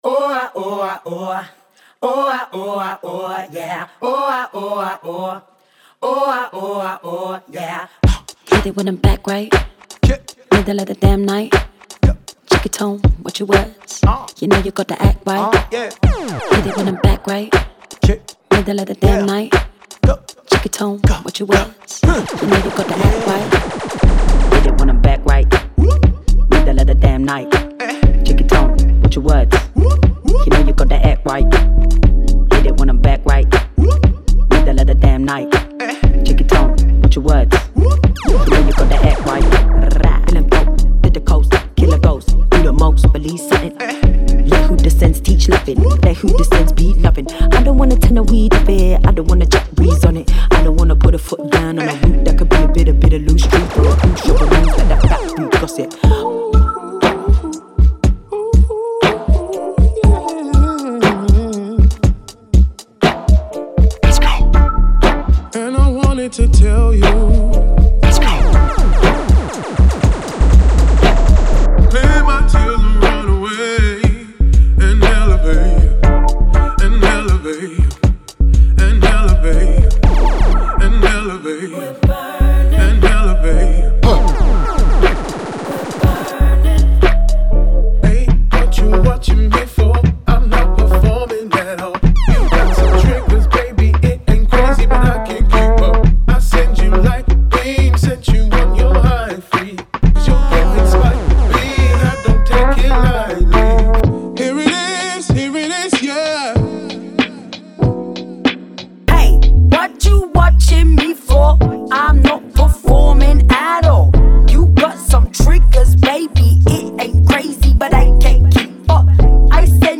Genre:Hip Hop
本作は個性と多様性に満ちた唯一無二のサンプルパックであり、60〜160 BPMまでのテンポに対応。
これらのボーカルは、リズムとメロディーが豊かに重なり合う土台の上に重ねられています。
スムーズ、グリッティ、ソウルフルなど、どの音にも生命力と個性が満ちており、すべてのサウンドがエネルギーを持って響きます。
デモサウンドはコチラ↓
118 Female Vocal Loops
90 Male Vocal Loops